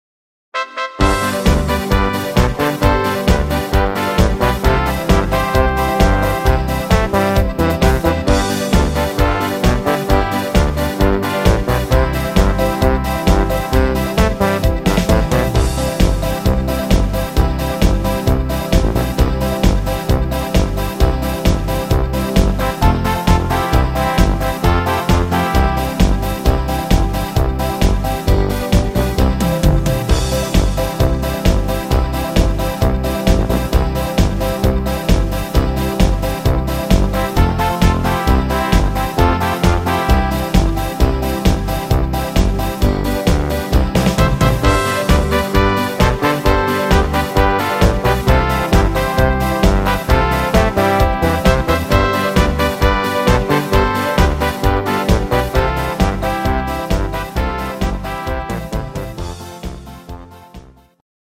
Rhythmus  Beat Polka
Art  Volkstümlich, Deutsch